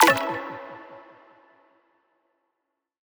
button-play-select.wav